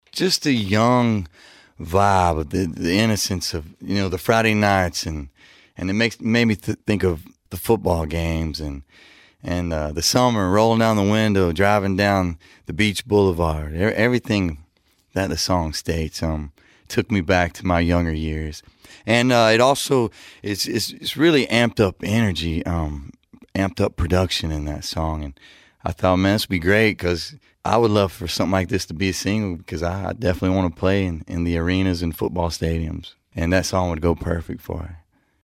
Billy Currington says his single, “We Are Tonight,” reminds him of his childhood summers.